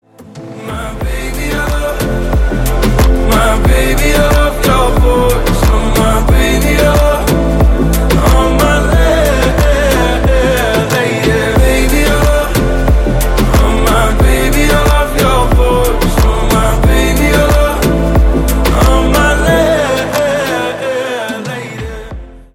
• Качество: 128, Stereo
поп
спокойные